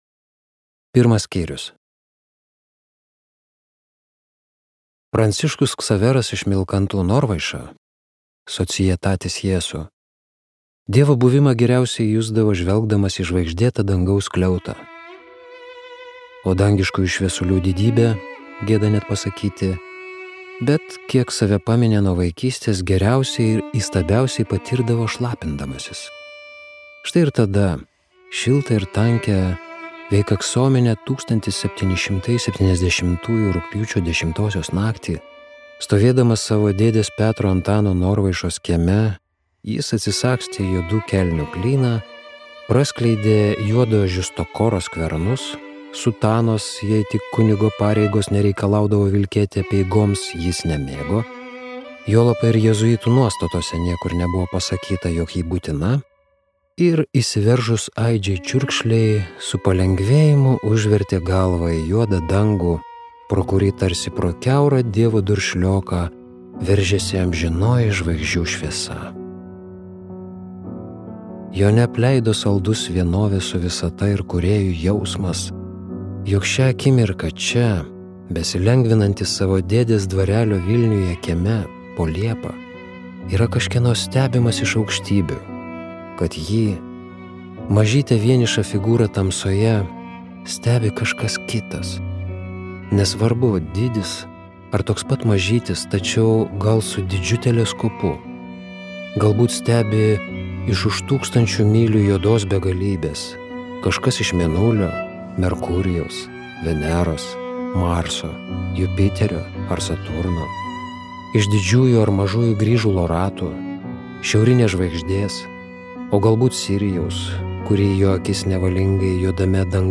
„Silva rerum IV“ audioknyga tęsia Norvaišų giminės istoriją ir užbaigia didžiąją Vilniaus sagą.